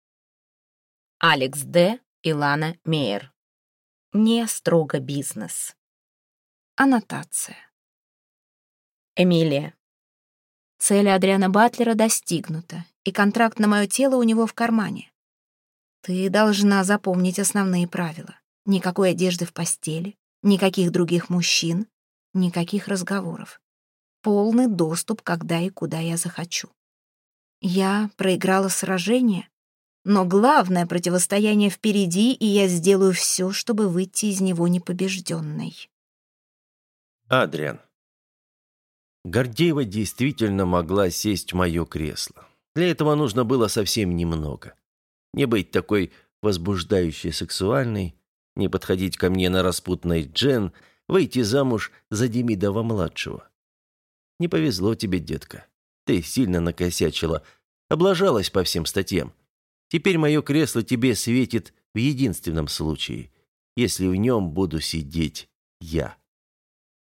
Аудиокнига (Не)строго бизнес | Библиотека аудиокниг